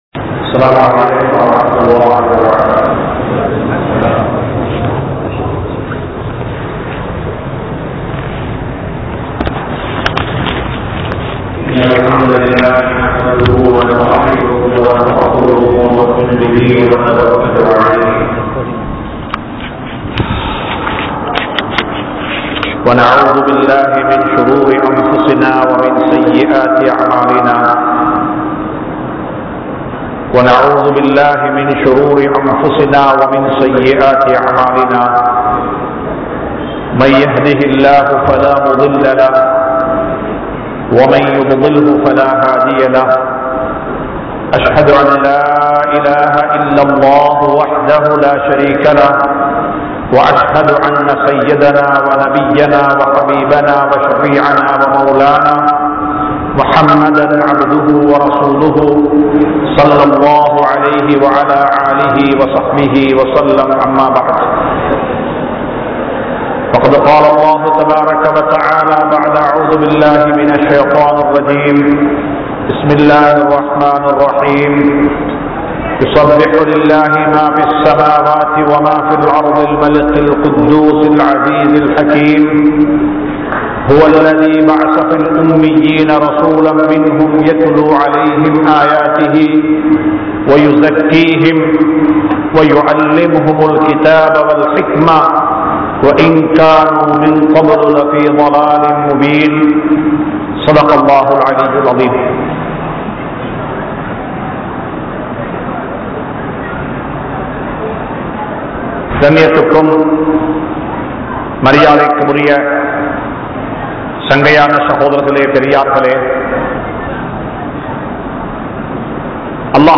Dhauwath Thableeq Entraal Enna? (தஃவத் தப்லீக் என்றால் என்ன?) | Audio Bayans | All Ceylon Muslim Youth Community | Addalaichenai